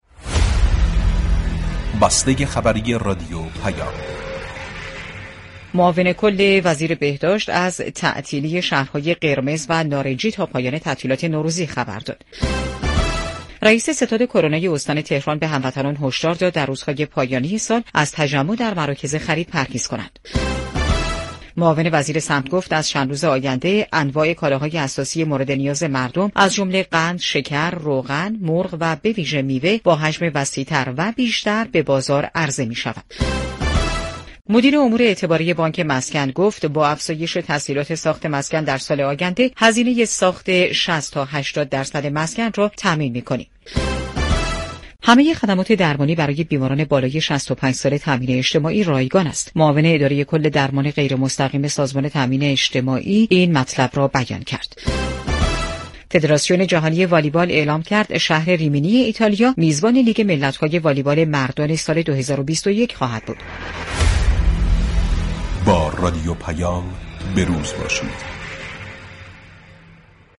گزیده ی اخبار رادیو پیام